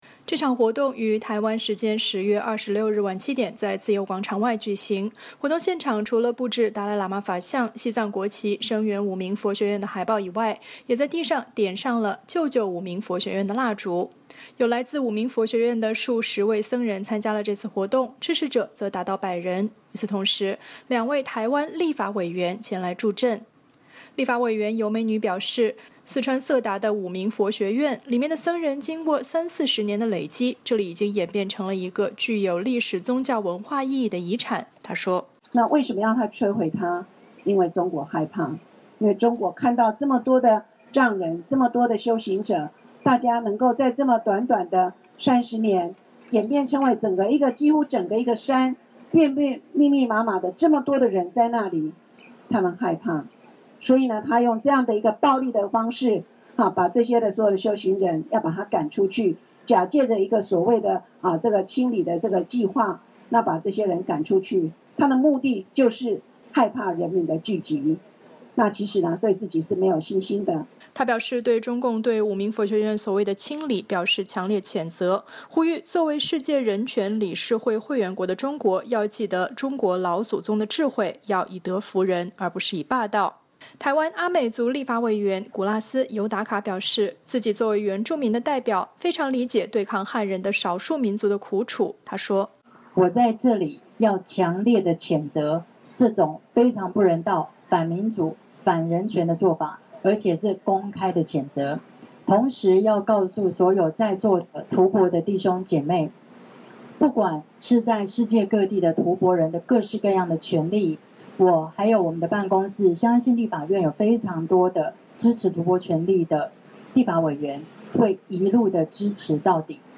活动现场除了布置达赖喇嘛法像、西藏国旗、声援五明佛学院的海报以外，也在地上点上了Save Larung Gar（意为救救五明佛学院）的蜡烛。